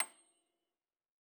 53j-pno30-C7.wav